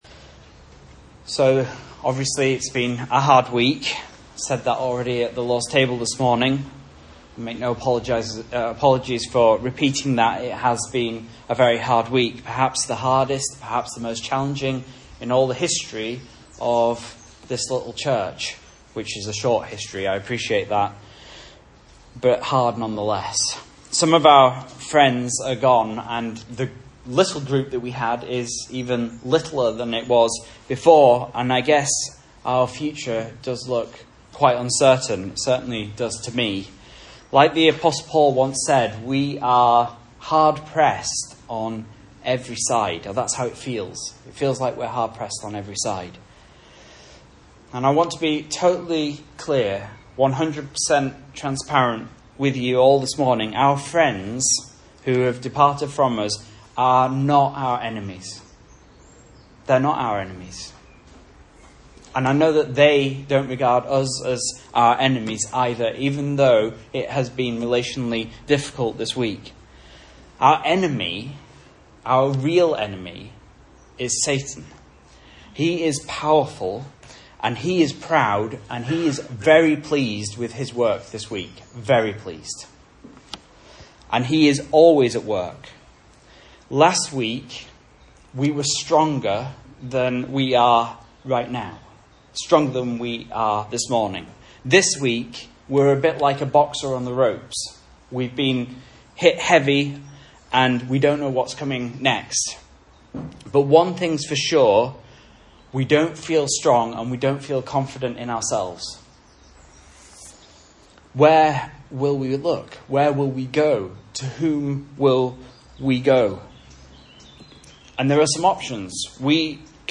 Message Scripture: 2 Chronicles 20:1-30 | Listen